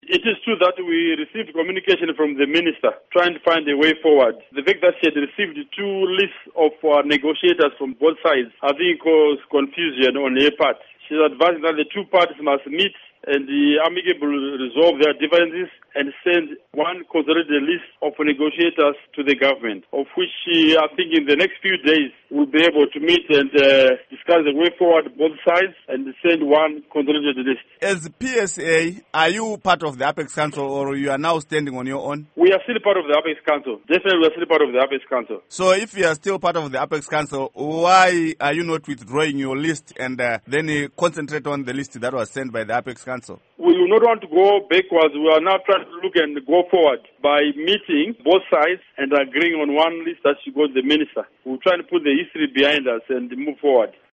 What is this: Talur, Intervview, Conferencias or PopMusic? Intervview